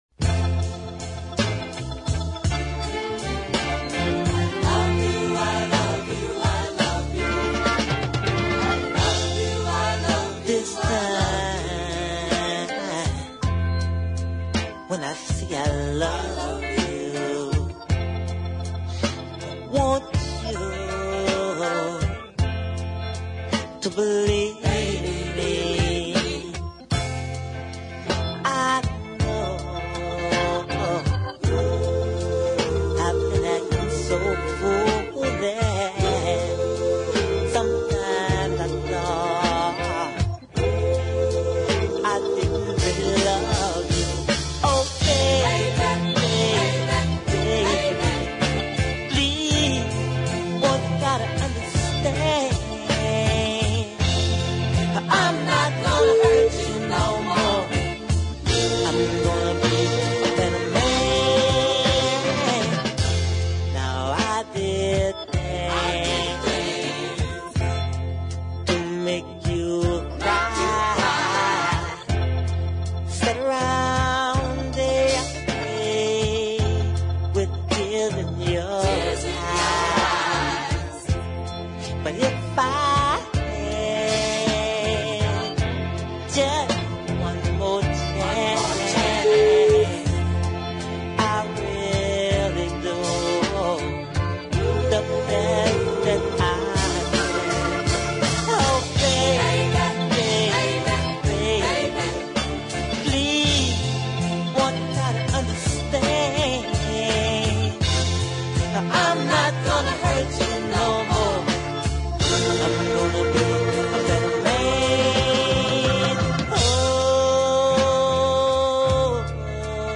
insidious ballad
More superb 70s group soul.